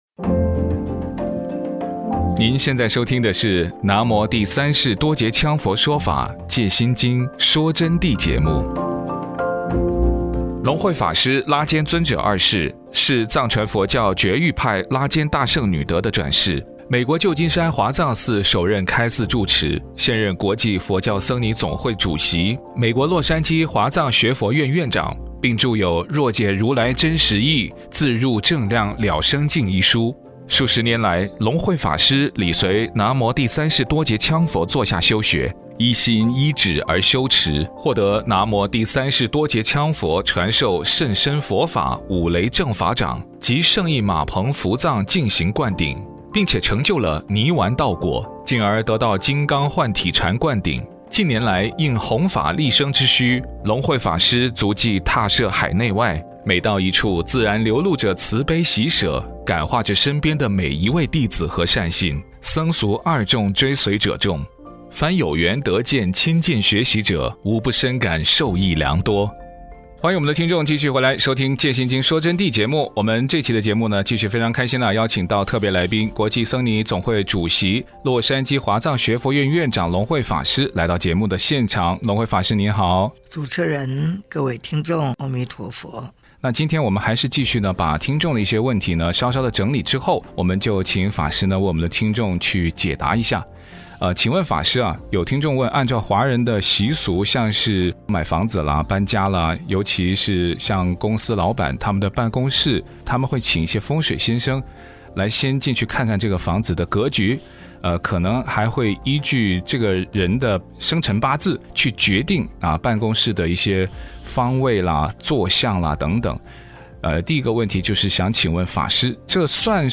電臺訪談评论757阅读模式